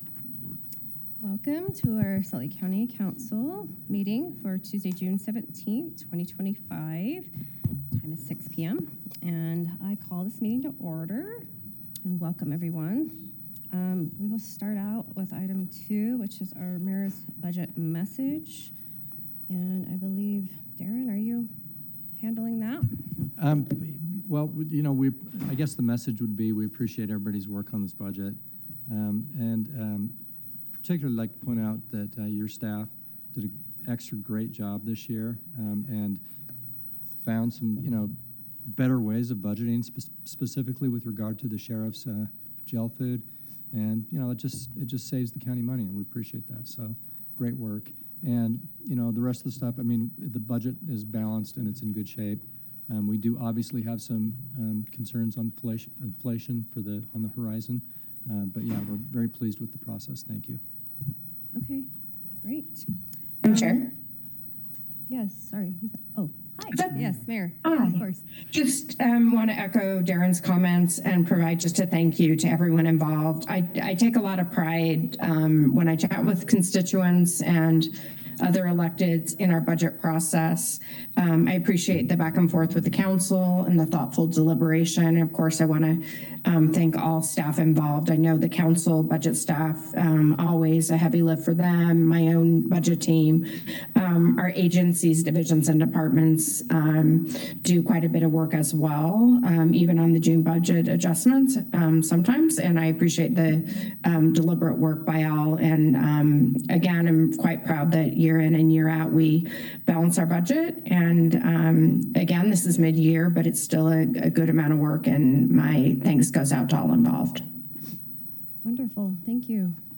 Members Of The Council May Participate Electronically.